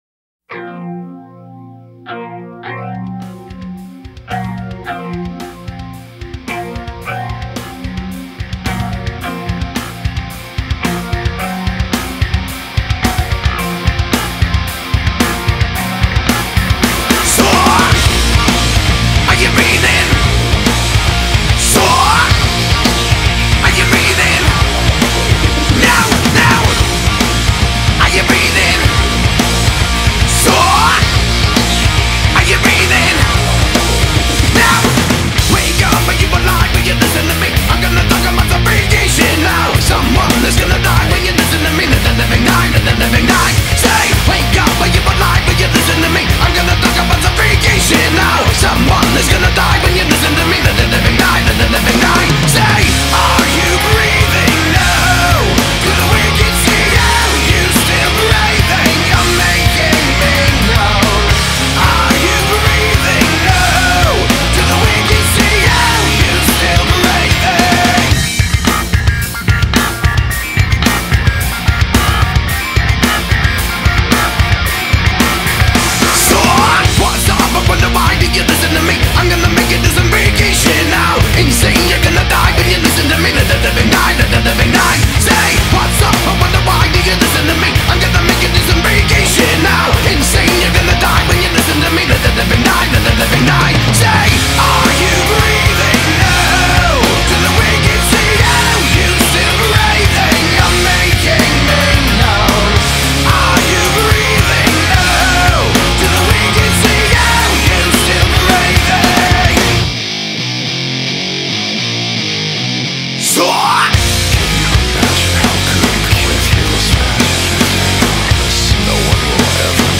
Nu Metal / Alternative Metal